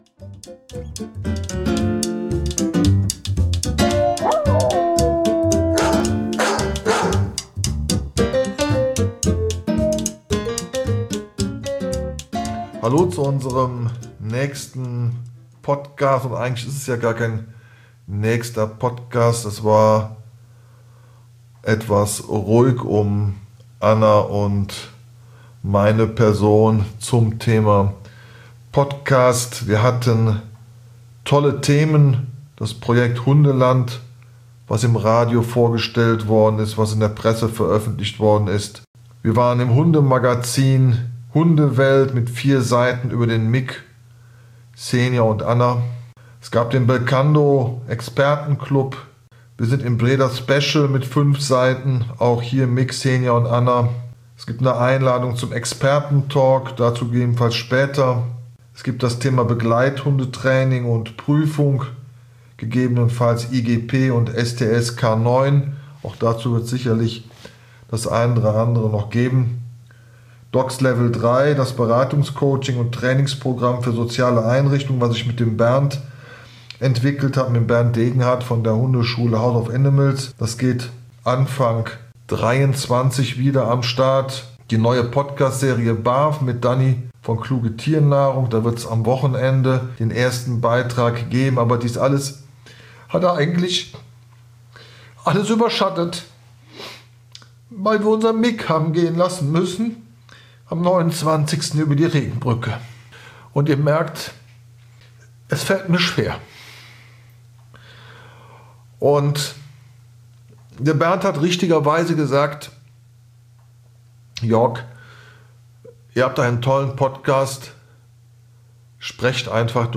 Mit Gebell und Stimmen aus der Hundeszene gibt es besondere Einblicke zum Thema: Alltagssituationen, Kennel und Zucht, Reisepodcast sowie Welpenzauber.